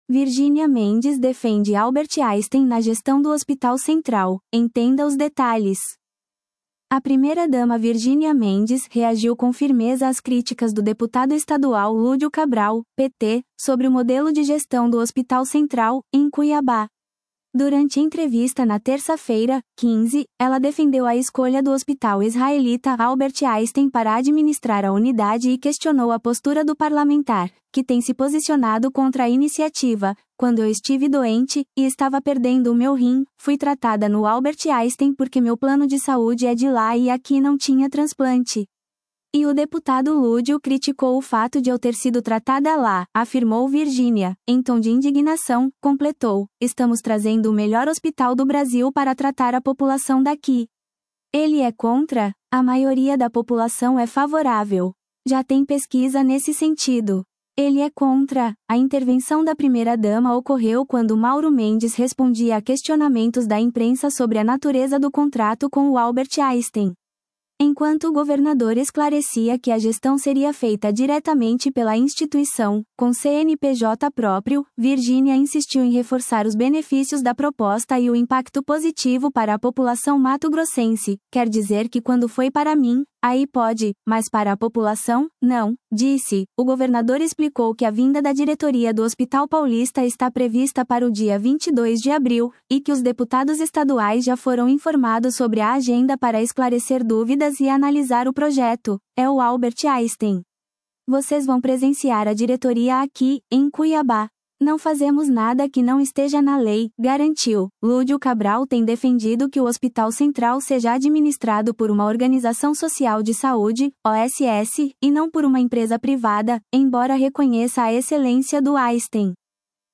Durante entrevista na terça-feira (15), ela defendeu a escolha do Hospital Israelita Albert Einstein para administrar a unidade e questionou a postura do parlamentar, que tem se posicionado contra a iniciativa.